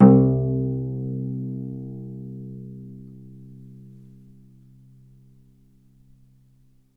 vc_pz-C2-ff.AIF